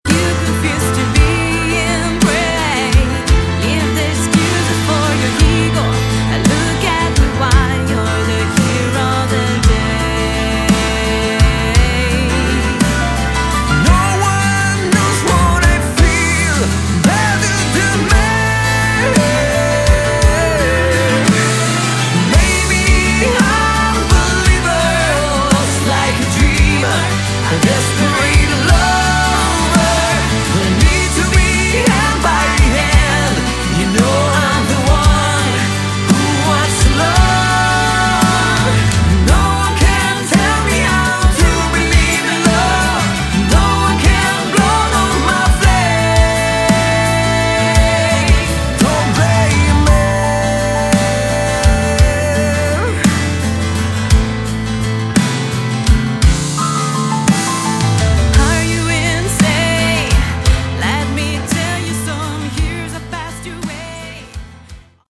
Category: Hard Rock / AOR / Prog